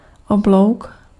Ääntäminen
US : IPA : /ɑɹtʃ/ UK : IPA : /ɑːtʃ/